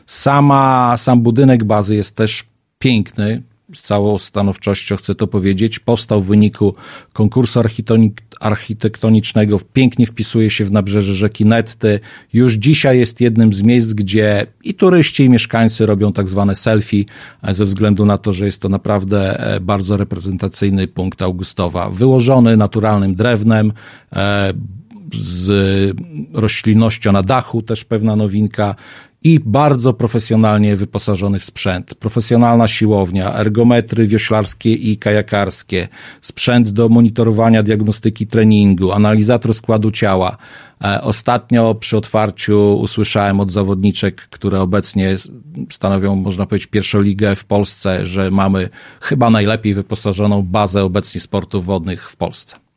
W sobotę (28.09.19) obyło się uroczyste otwarcie, a we wtorek (01.10.19) Mirosław Karolczuk, burmistrz miasta opowiedział w Radiu 5 o tej inwestycji.